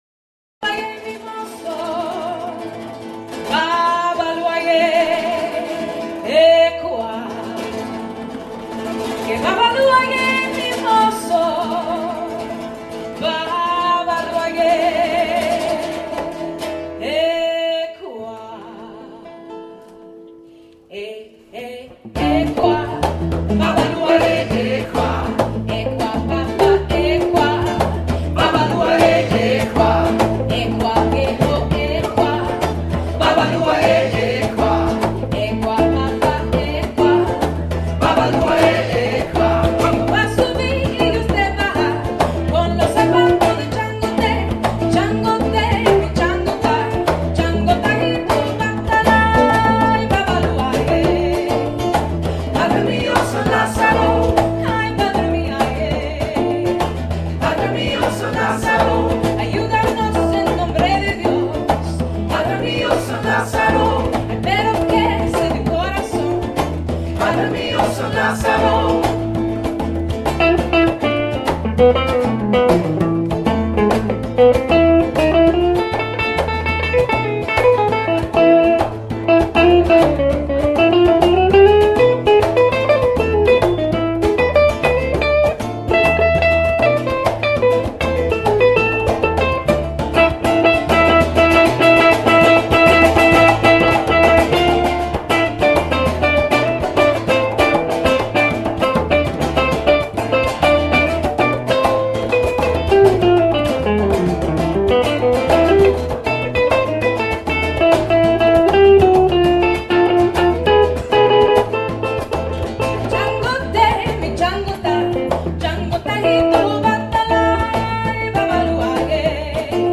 energisk og dansevenlig musik fra over alt i latin Amerika
• Salsa/reggae/latin